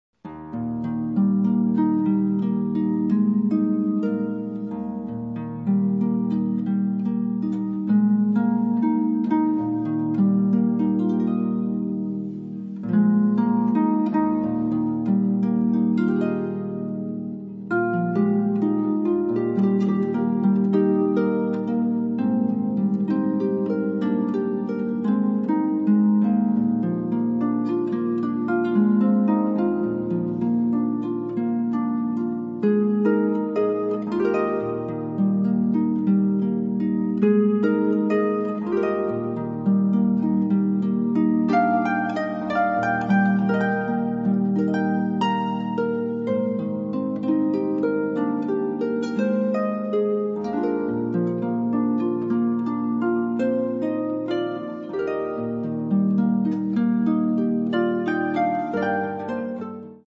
Genre: Pop & Jazz